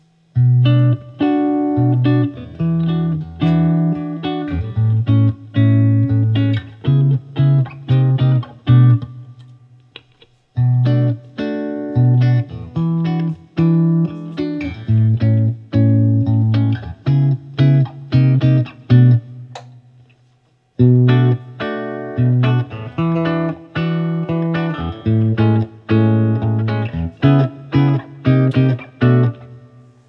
Guild-1994-X170-SB-BackAngleThis is a very bright guitar, but the tone controls are so useable that it’s an amazingly versatile instrument as well.
As usual, all recordings in this section were recorded with an Olympus LS-10. The amp is an Axe-FX Ultra set to “Tiny Tweed” which was left stock.
Fingerstyle on 3
Note that this guitar is strung up with Ernie Ball Regular Slinky strings which are round-wound so you’re gonna get some finger noise in there.